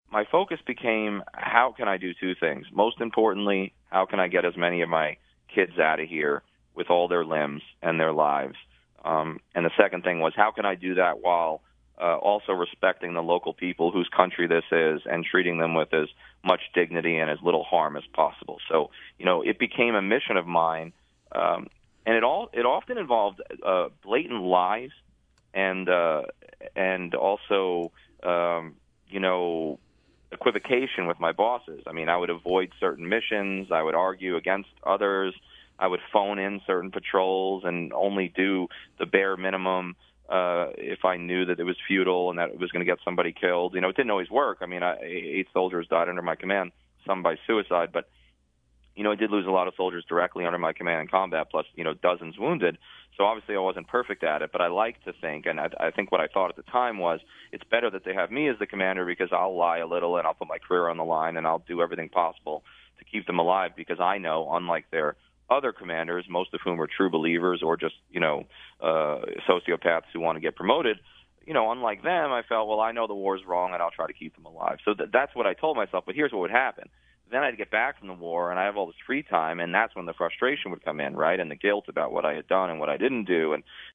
In this wide-ranging interview, we touch on many other topics, including Netanyahu’s efforts to retain power in Israel, along with bipartisan support for regime change in Venezuela.